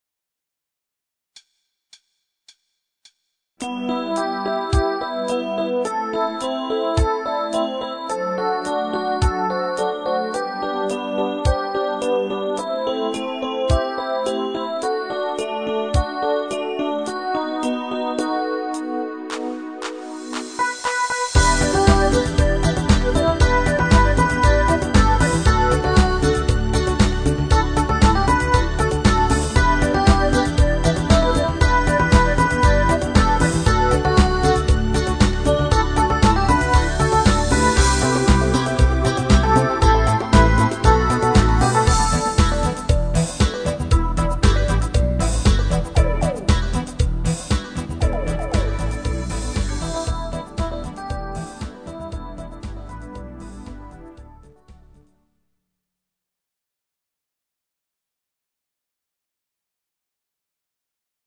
Genre(s): Deutschpop  Partyhits  |  Rhythmus-Style: Funk